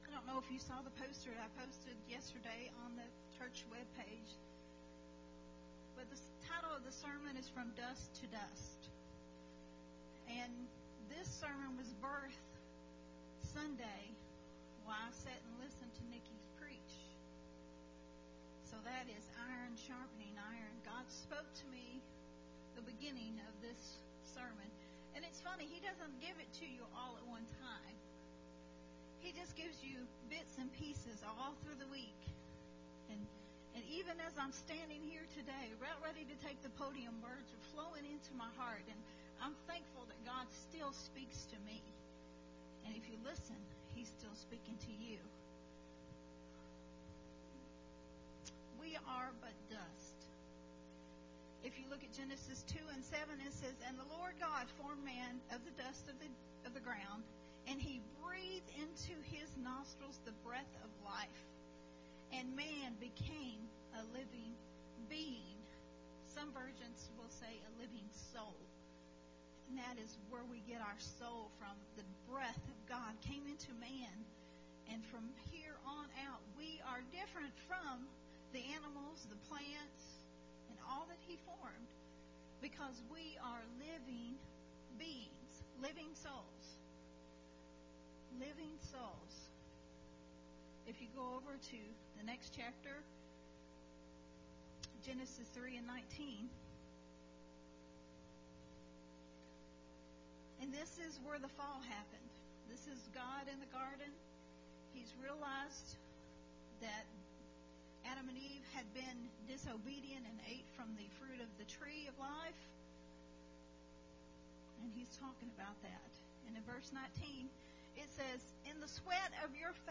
recorded at Unity Worship Center on March 13th, 2022.